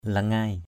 /la-ŋaɪ/ (cv.) lingai l{=z (đg.) tự tiện = qui en prend à son aise. take oneself easy as at home, instinctively.